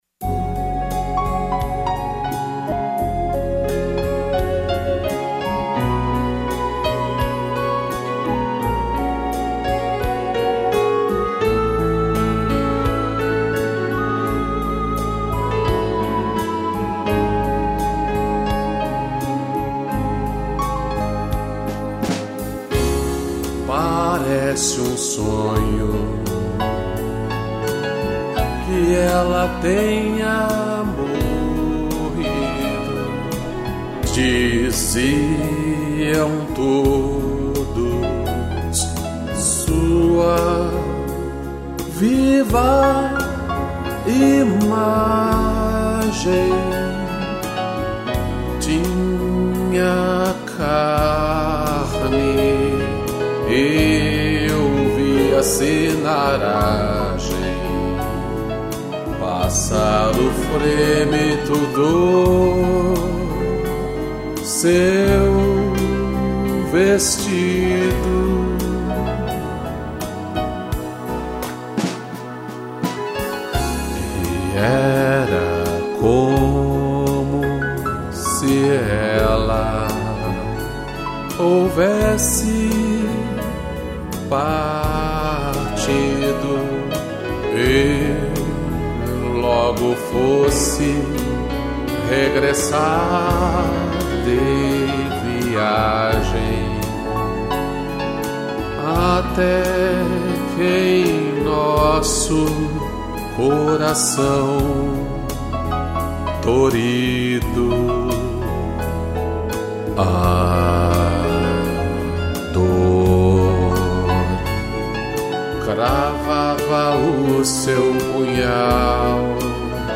piano, violino e cello